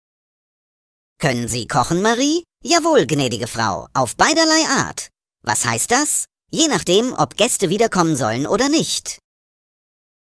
Die Witzschmiede bringt Dir jeden Tag einen frischen Witz als Audio-Podcast. Vorgetragen von unseren attraktiven SchauspielerInnen.
Comedy , Unterhaltung , Kunst & Unterhaltung